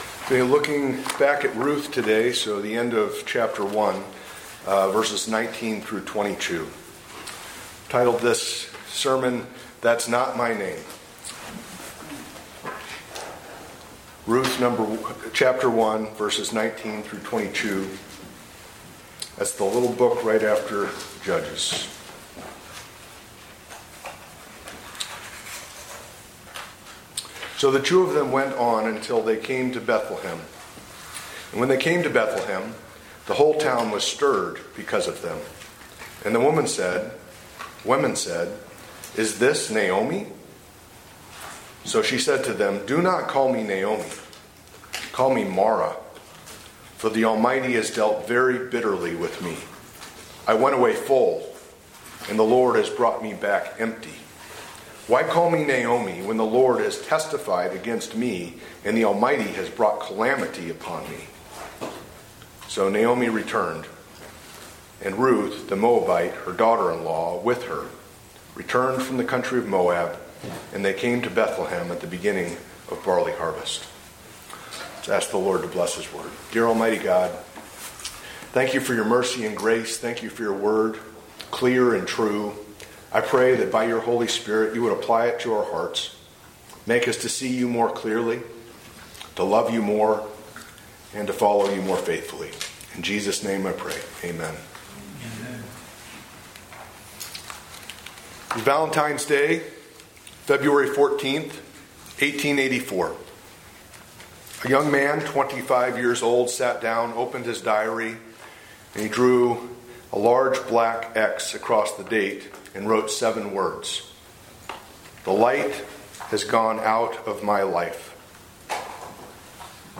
A message from the series "Guest Preacher."
From Series: "Guest Preacher"